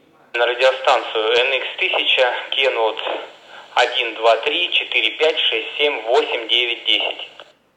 Пример модуляции (передачи) радиостанции NX-1200, NX-1300 в аналоговом режиме:
nx-1000-tx-analog.wav